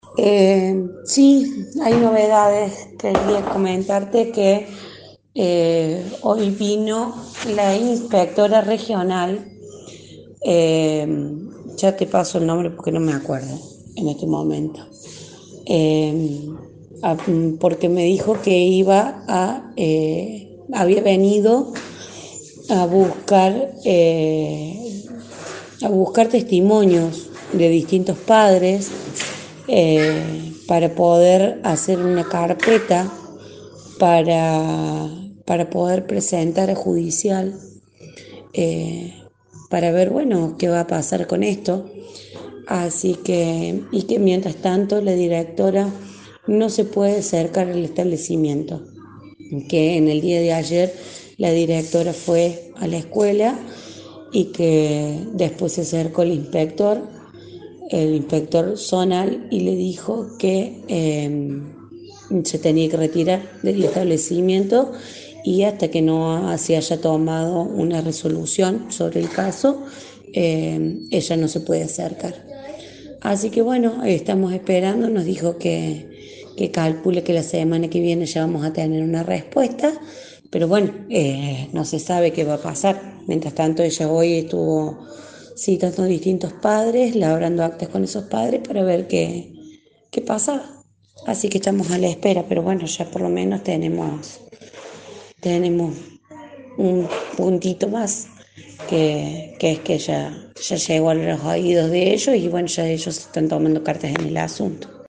Indicó que la Directora de la institución denunciada, no tiene permitido ingresar al establecimiento, según nos relata una madre de un alumno de la escuela en una nota que realizamos en «La Mañana Informal».